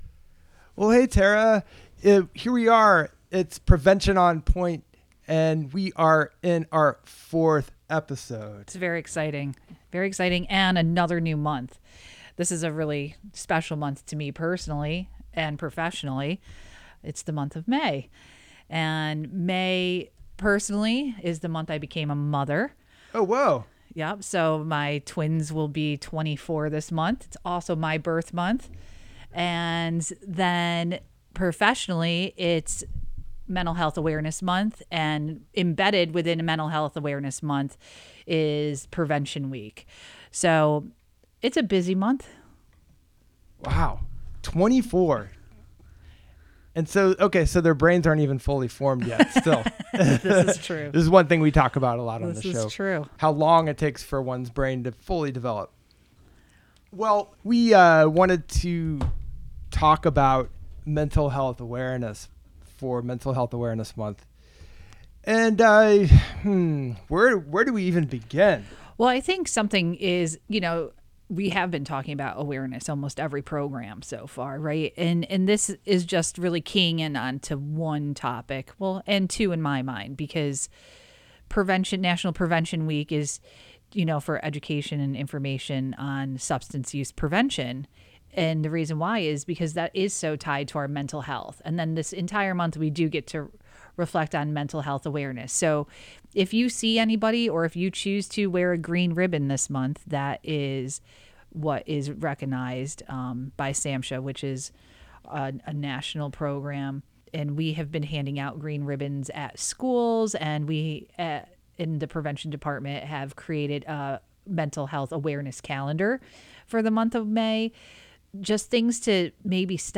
Lively conversation and useful information about substance use and misuse, prevention, wellness, and community.